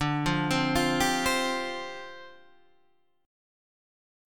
Dm11 chord